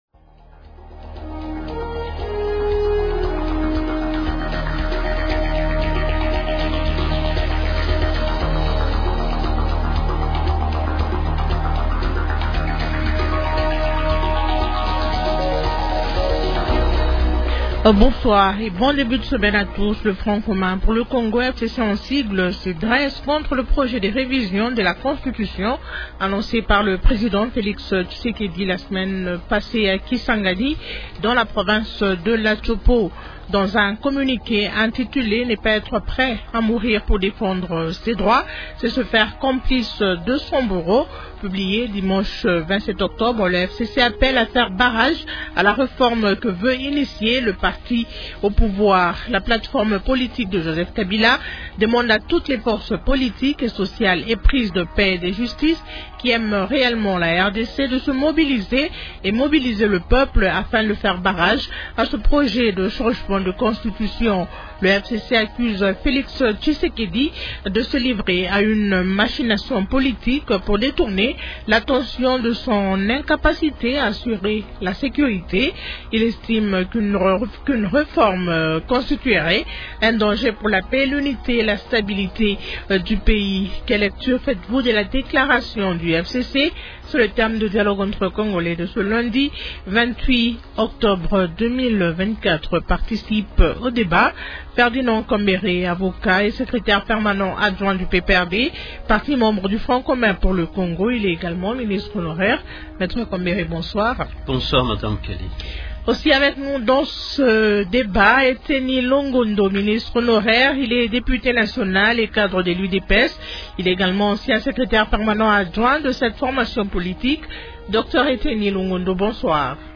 Question : -Quelle lecture faites-vous de la déclaration du FCC ?